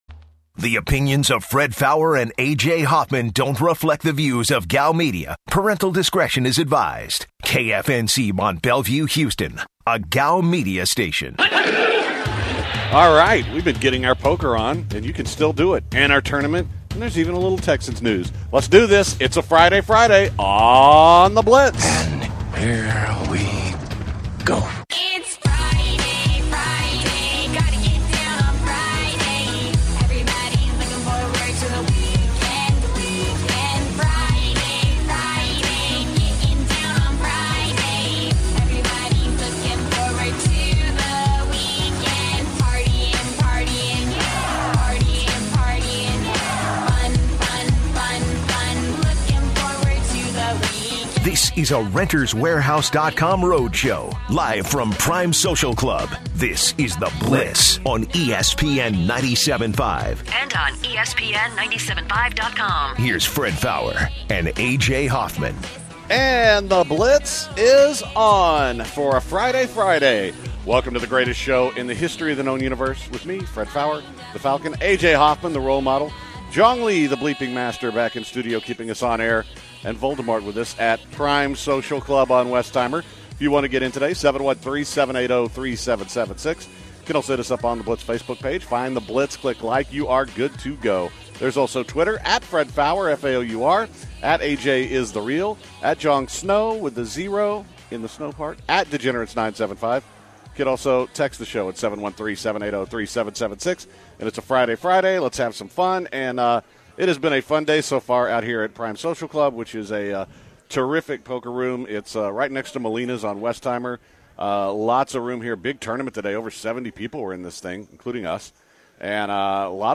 The Blitz opens up from Prime Social Club as the guys talk about their poker tournaments today. The guys recap yesterday’s games and also the games that concluded this afternoon. They break down the UH game in more detail and give their thoughts on what they think will happen in the next round for teams.